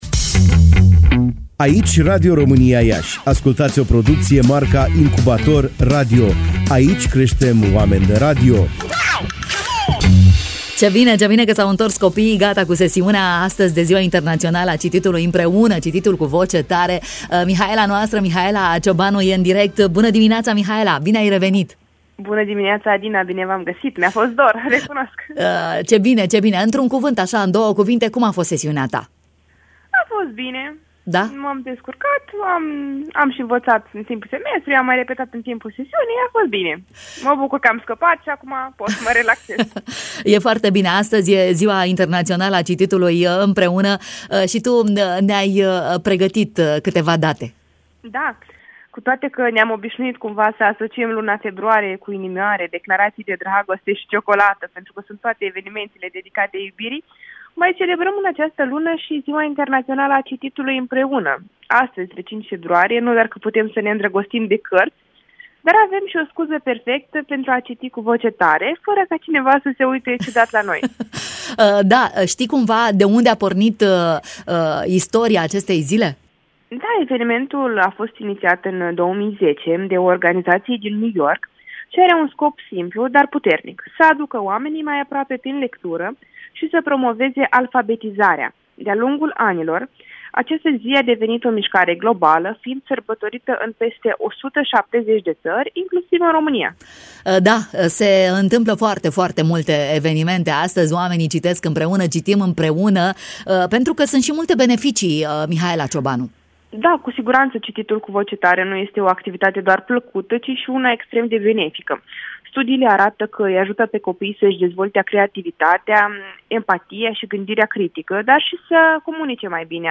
ne-a creionat, în direct, semnificația acestei zile.